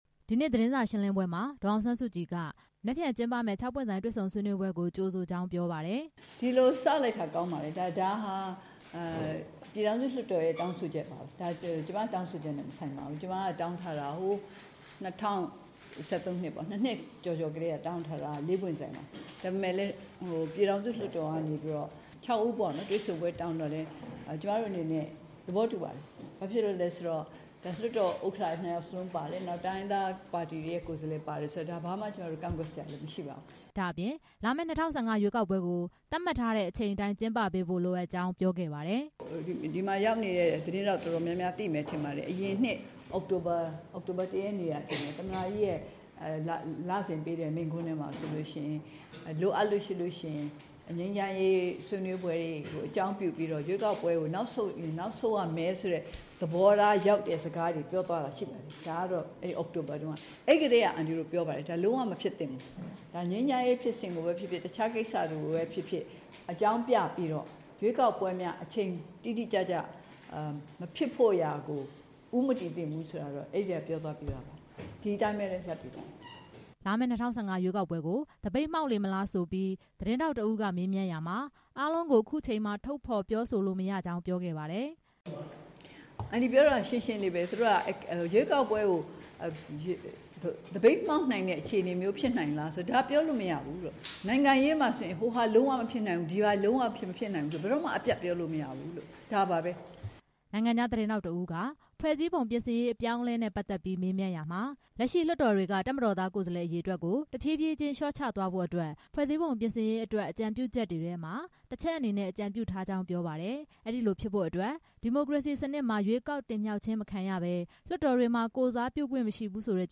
မနက်ဖြန် ခေါင်းဆောင် ၆ ဦးရဲ့ ၆ ပွင့်ဆိုင် တွေ့ဆုံဆွေးနွေးမှုမတိုင်ခင် ဒီနေ့ အမျိုးသားဒီမိုကရေစီအဖွဲ့ချုပ် ဥက္ကဌ ဒေါ်အောင်ဆန်းစုကြည်က နေပြည်တော် တရားဥပဒေစိုးမိုးရေးနဲ့ တည်ငြိမ်အေးချမ်းရေးကော်မတီ ရုံးခန်းမှာ သတင်းစာရှင်းလင်းပွဲ ကျင်းပခဲ့ပါတယ်။